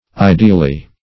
Ideally \I*de"al*ly\, adv.